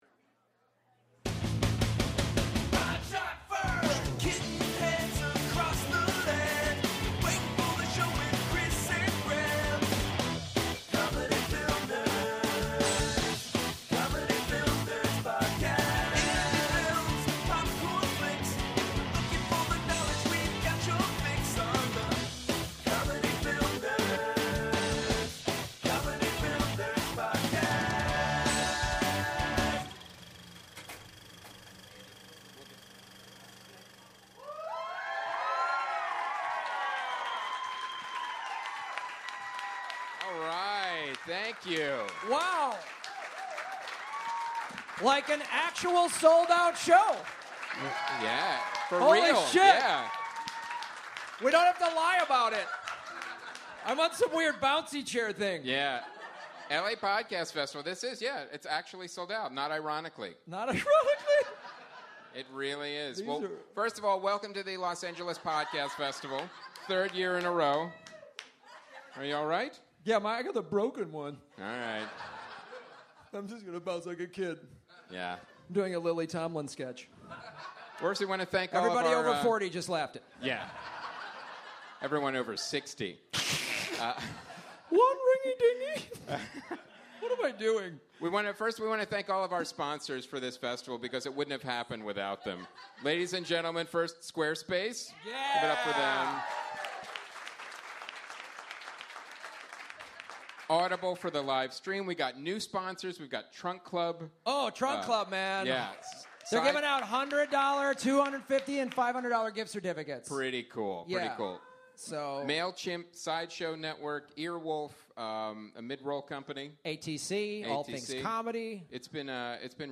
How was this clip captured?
Live from LA Podfest 2014!